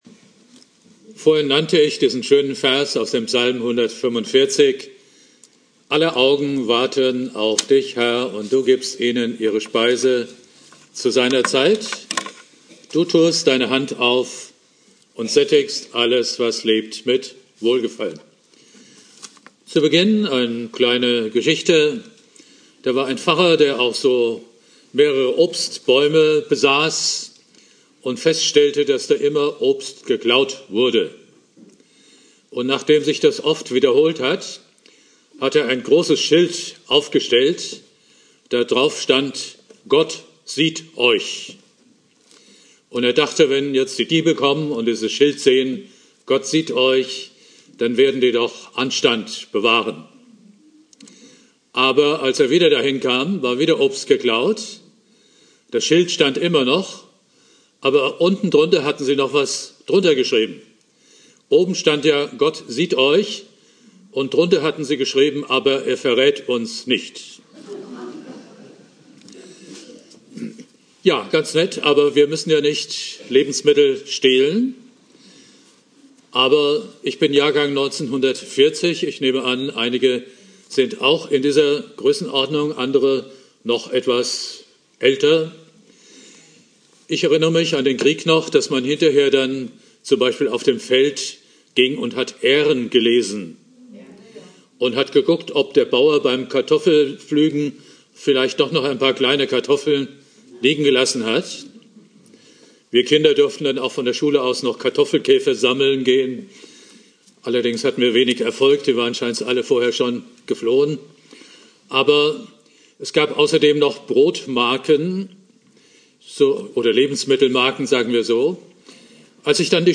Predigt
(im Haus Jona gehalten) Bibeltext: Psalm 145,15-16 Dauer: 19:10 Abspielen: Ihr Browser unterstützt das Audio-Element nicht.